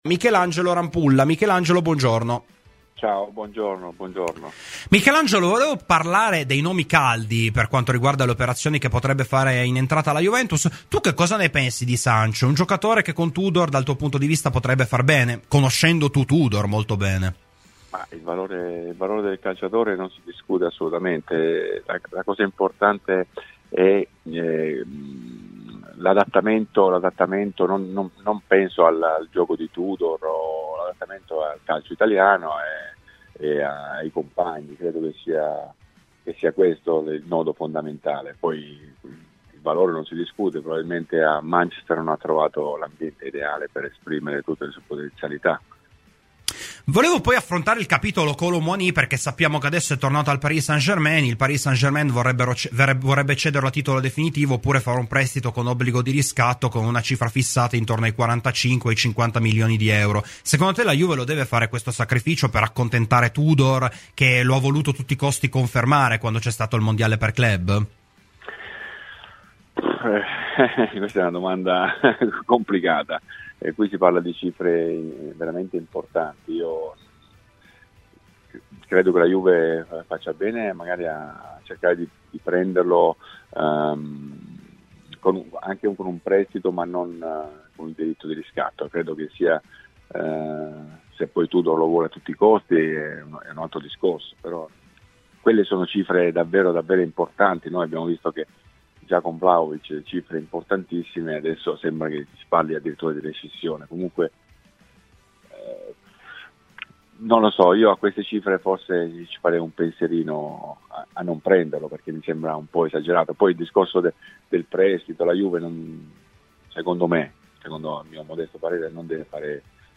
Michelangelo Rampulla è intervenuto a Radio Bianconera, nel corso della trasmissione Rassegna Stramba. Queste le considerazioni dell'ex portiere bianconero.